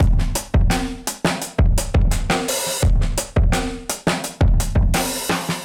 Index of /musicradar/dusty-funk-samples/Beats/85bpm/Alt Sound
DF_BeatB[dustier]_85-03.wav